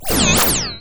sci-fi_power_down_02.wav